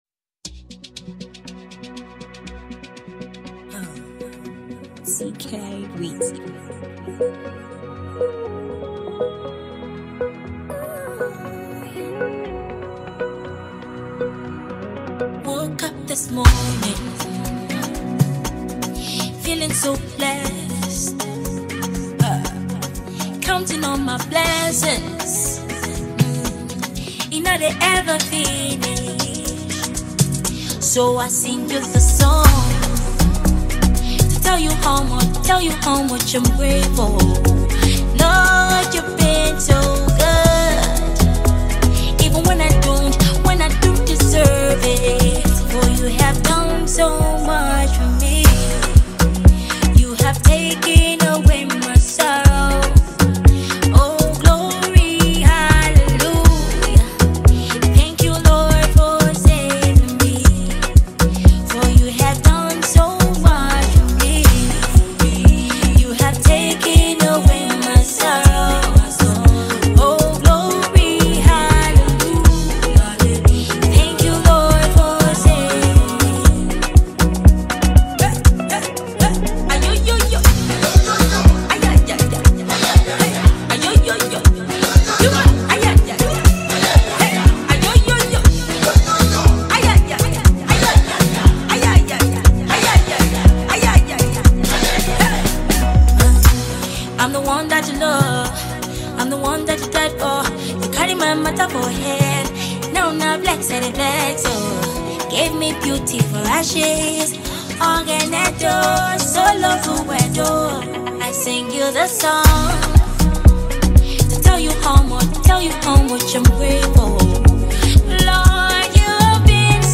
Award-winning Gospel artist
unique Afrocentric style of music
This song has an Afrobeat that will get you up on your feet.